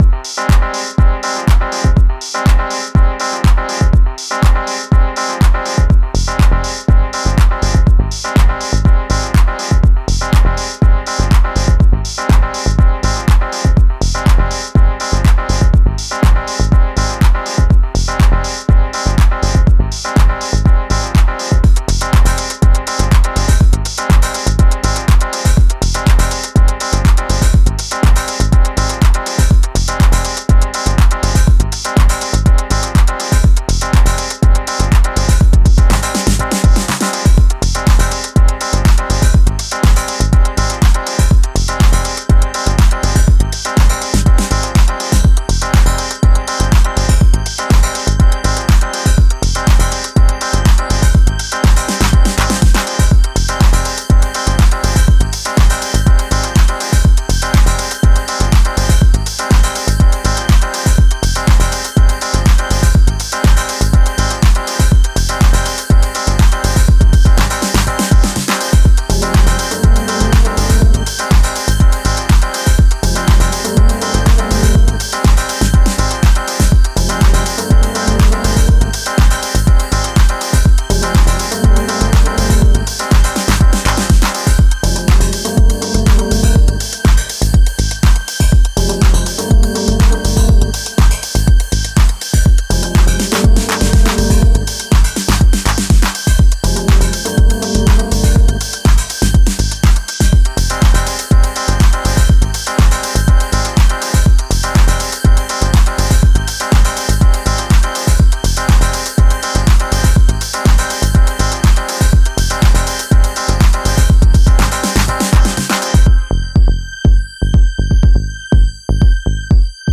ピアノ・リフを軸に据えながら展開していく